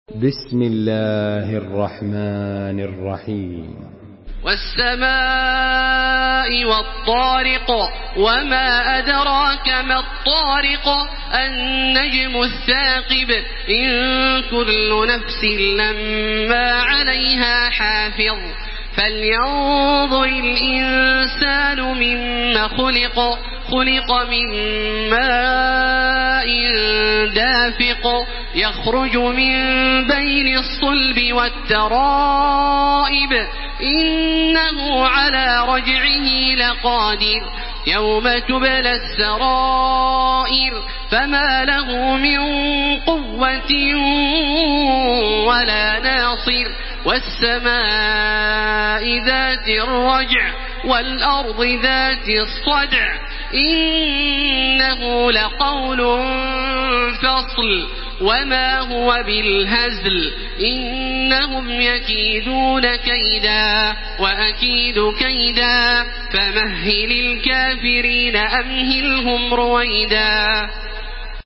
Surah At-Tariq MP3 by Makkah Taraweeh 1434 in Hafs An Asim narration.
Murattal